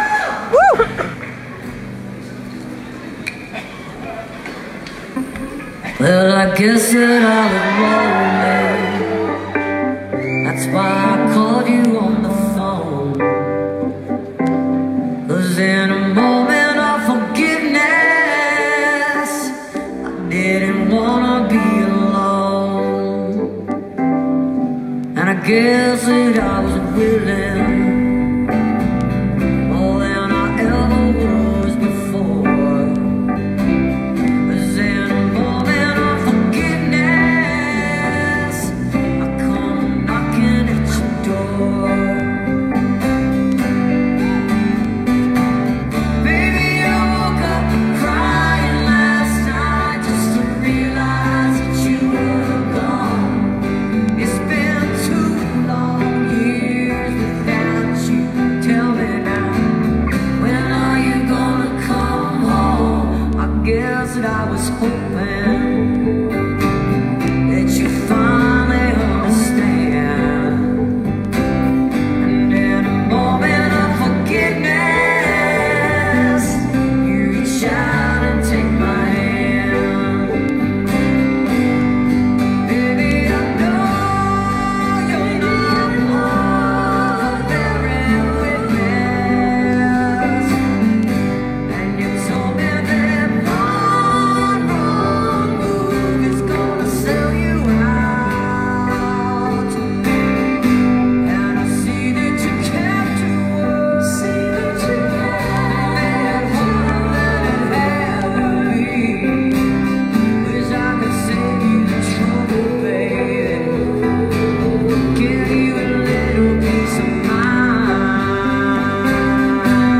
(captured from facebook live streams)